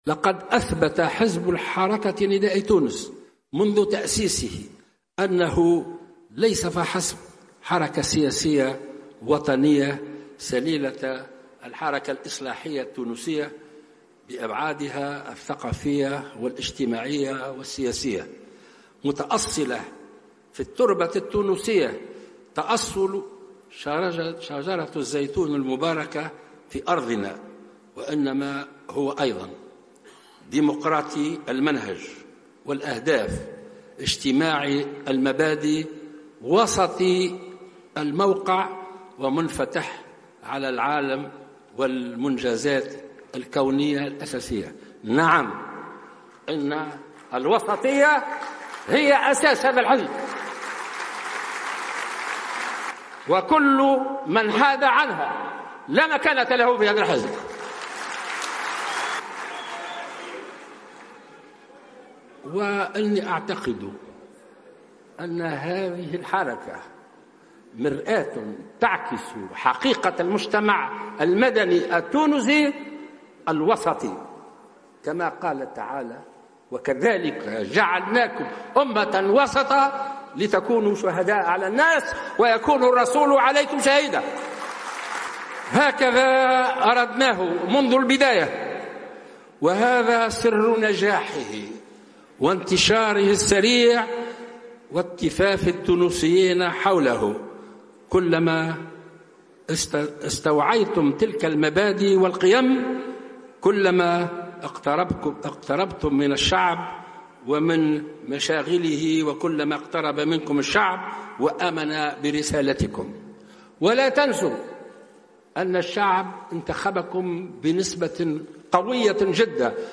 قال الباجي قائد السبسي في كلمة ألقاها اليوم السبت 09 جانفي 2016 في افتتاح أشغال المؤتمر الأول لحزب نداء تونس في سوسة إنه لا مكان لمن حاد عن الوسطية في النداء مشددا على أن الوسطية ستظل دائما أساس الحزب الذي تأسس منذ نحو 3 سنوات على حد تعبيره.